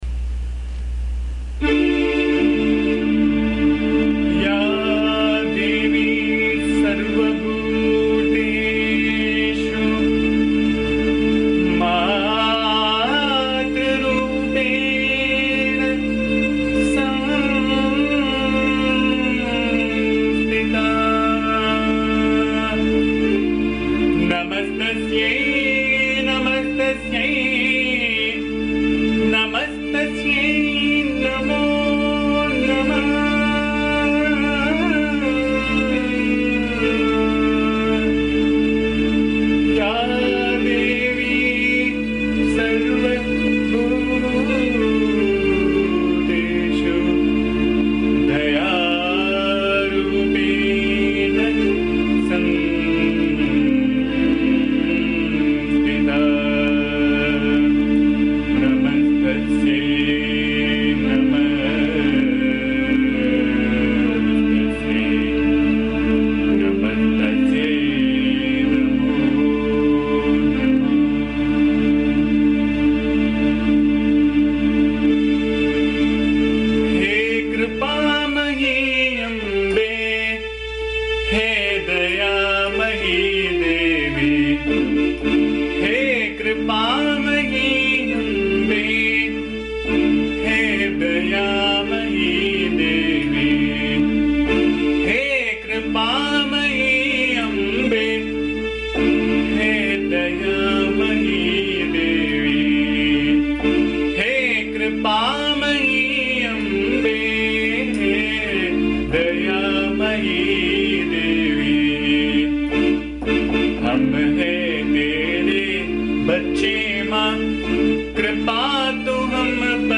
The song is set in Raaga Abheri (also known as Bhimpalas).
Please bear the noise, disturbance and awful singing as am not a singer.
AMMA's bhajan song